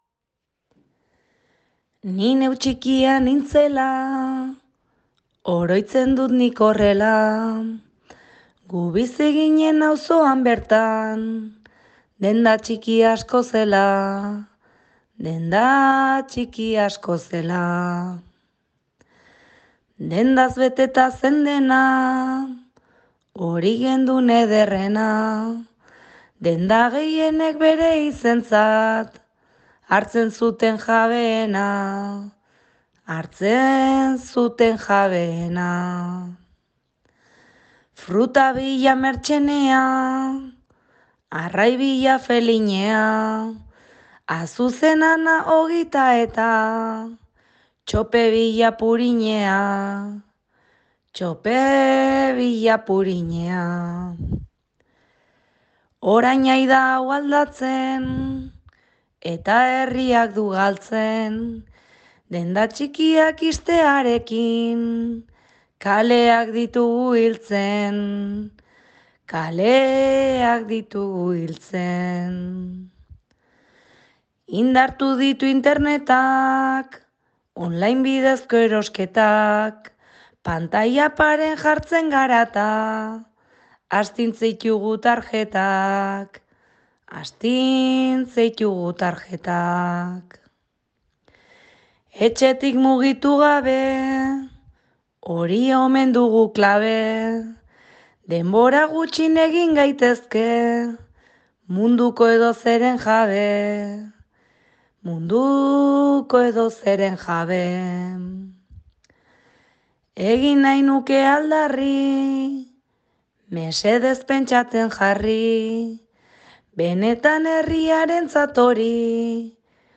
bertso sorta